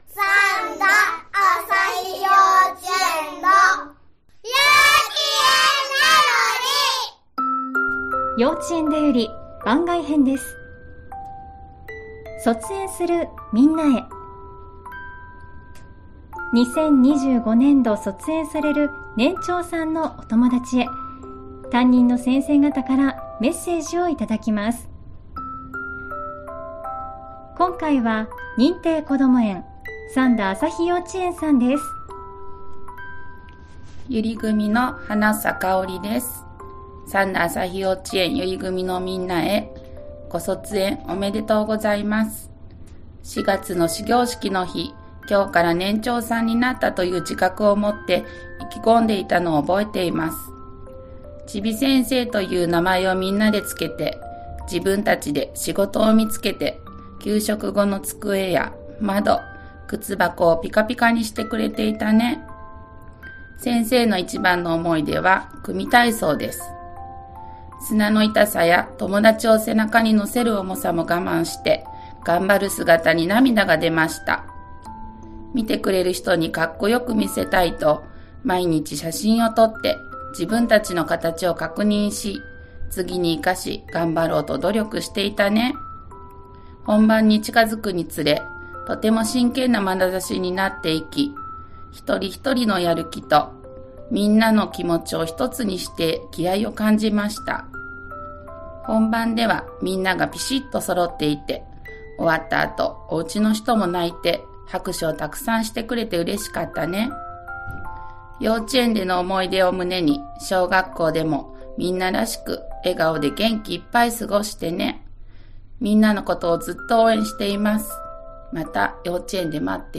幼稚園だより番外編、卒園するみんなへ🌸 2025年度卒園する年長さんへ、先生方からのメッセージをお届けします！